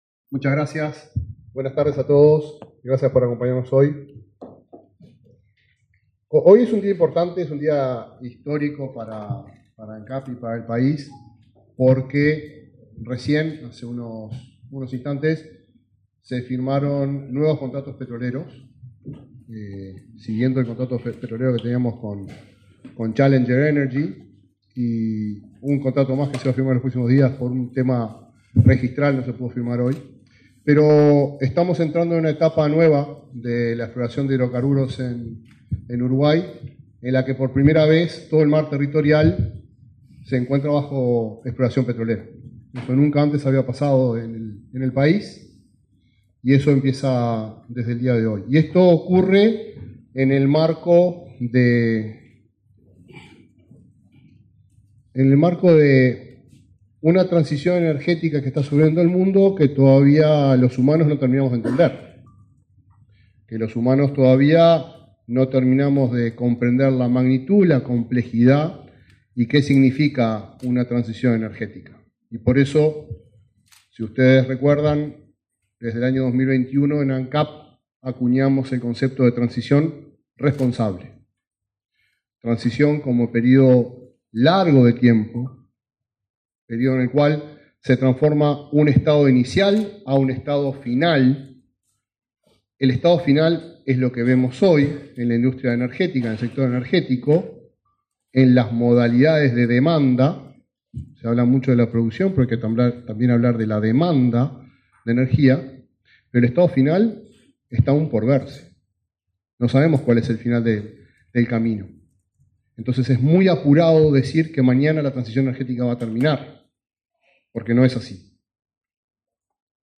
Conferencia de prensa de Ancap sobre una nueva etapa de exploración de hidrocarburos en las cuencas marinas
Este 12 de diciembre, el presidente de Ancap, Alejandro Stipanicic, y demás autoridades de la empresa estatal realizaron una conferencia de prensa